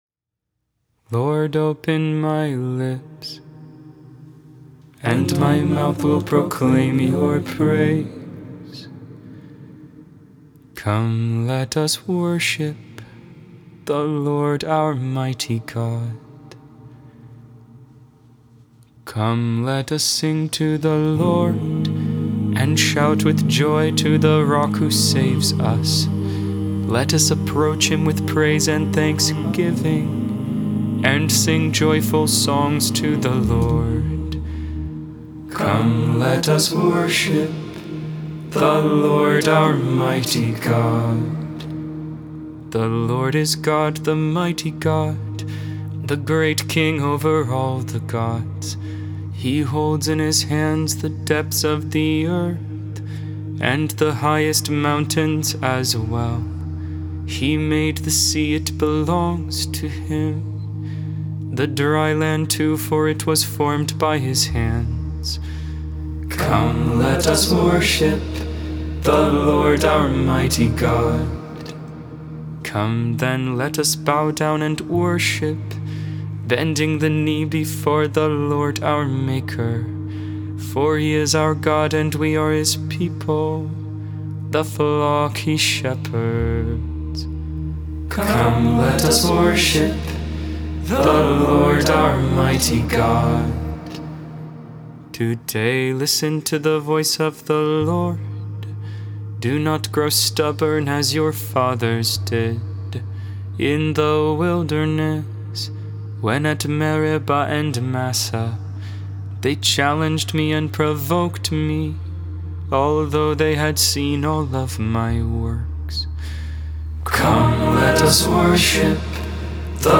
Hymn: "Aeterne Lucis Conditor." (English Version).